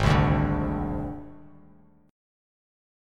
G#Mb5 chord